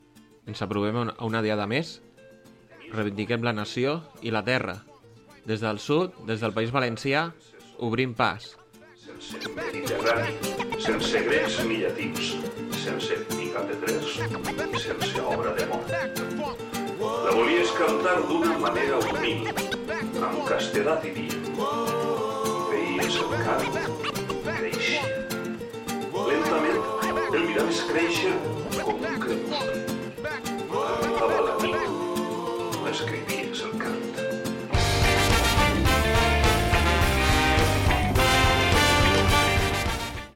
Comentari i tema musical
Musical